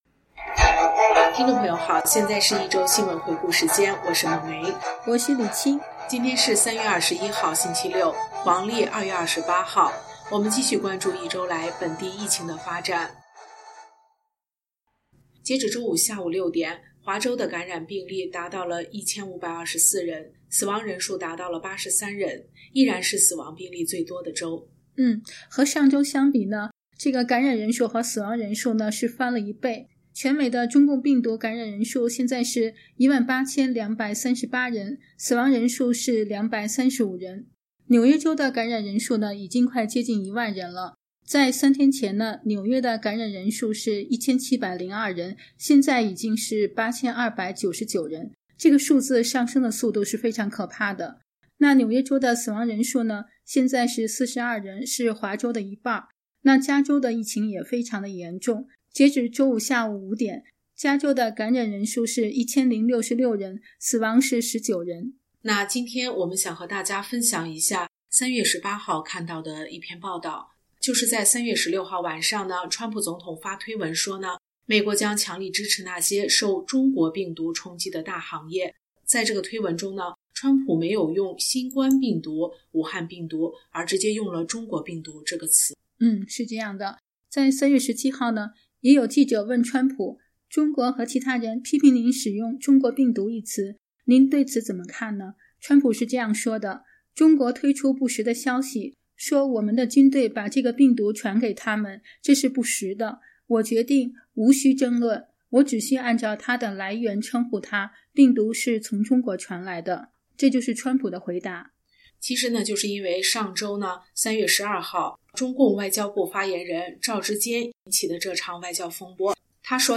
新聞廣播
每日新聞